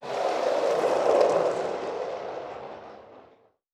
Skateboard Wheels Medium Speed.wav